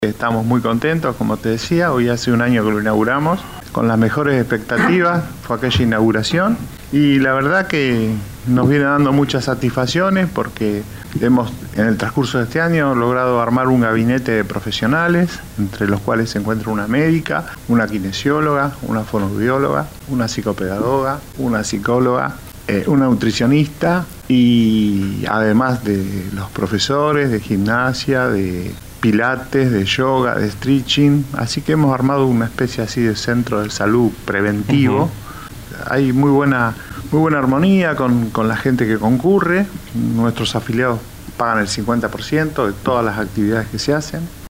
visitó los estudios de LU 24